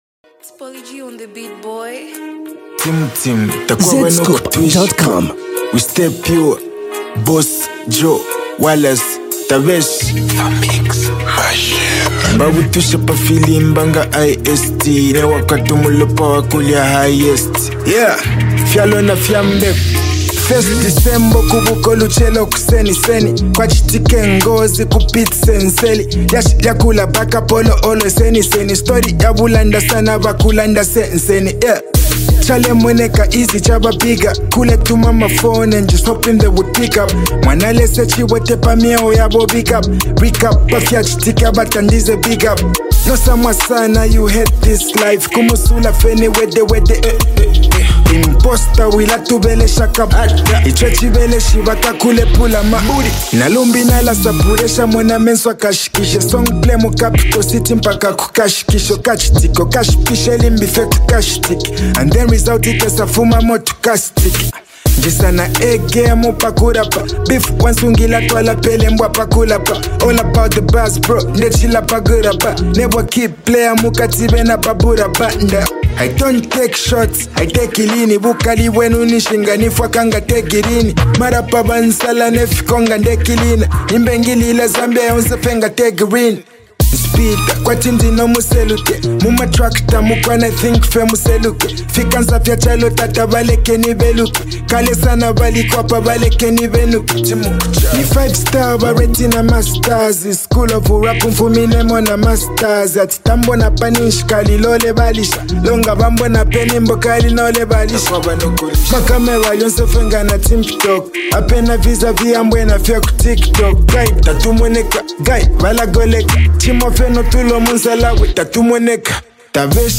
rap
hip-hop